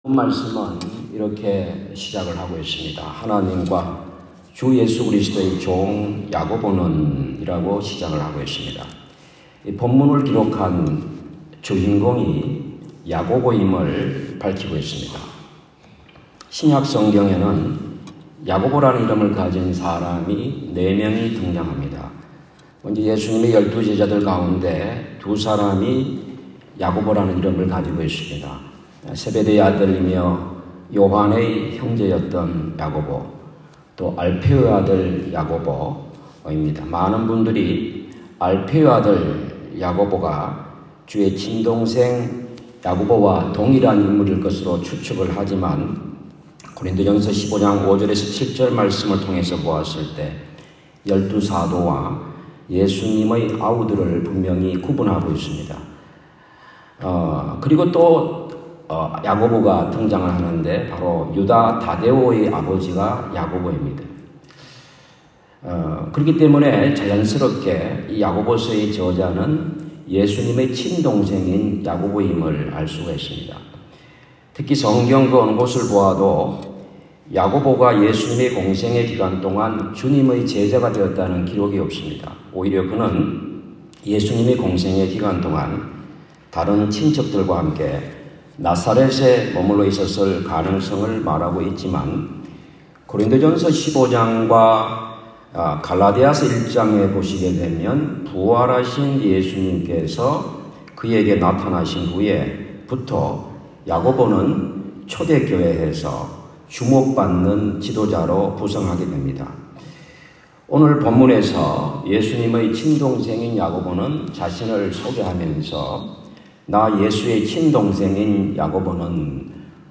원주 감리교 31개 교회 연합성회 새벽
9월-19일-원주-새벽-부흥회-설교.m4a